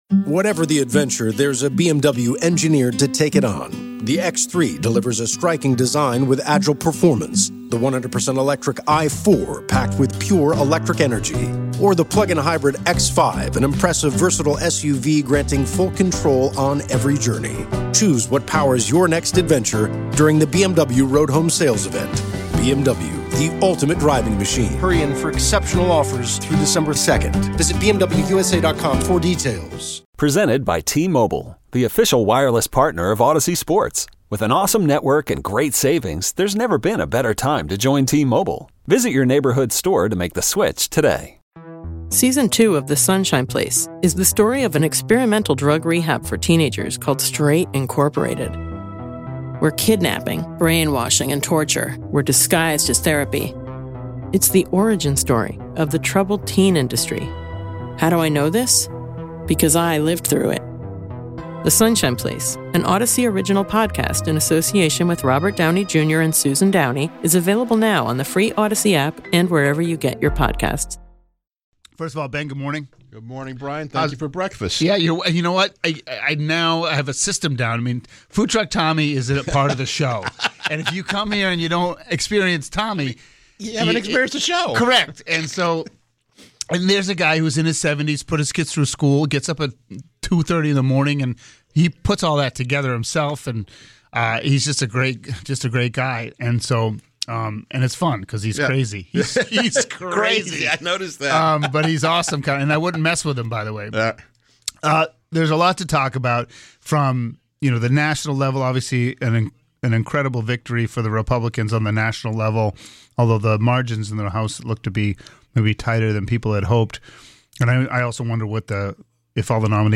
News, weather, traffic and sports plus, interviews with the people making the news each day.